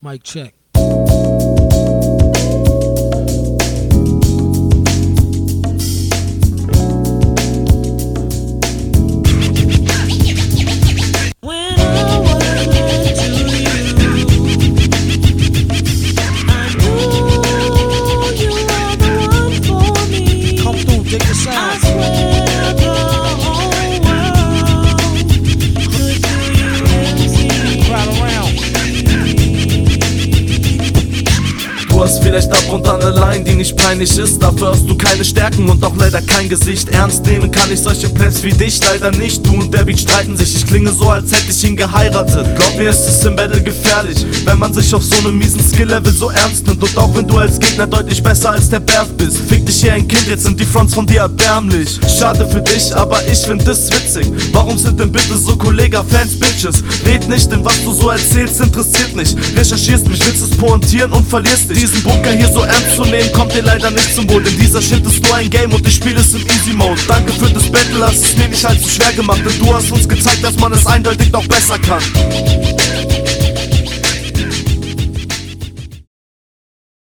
Sehr cooler Beat.
Bisschen sehr langes Intro.